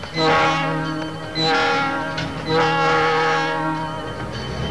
The Newburgh & South Shore, a switching railroad located in Cleveland, OH, had an unusual horn mounted on one of their locomotives in the mid-90's. It consisted of a Wabco E-2 and a pair of smaller horn bells mounted to the right of the E-2 on the roof of the locomotive. The horn was a homemade arrangement cobbled together from second-hand single-note honkers of varying pedigree. The sound is not likely to be confused with anything else.
NSS horn samples: